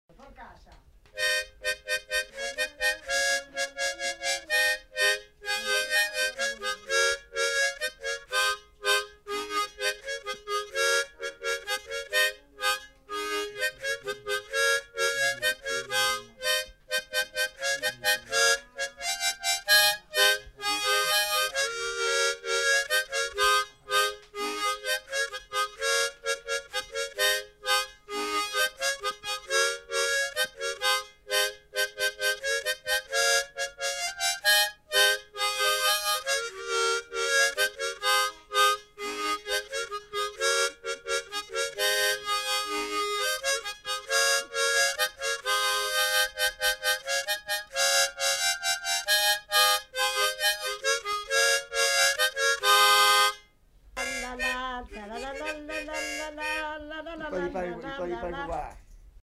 Polka
Genre : morceau instrumental
Instrument de musique : harmonica
Ecouter-voir : archives sonores en ligne